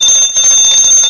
00008_Sound_phone.mp3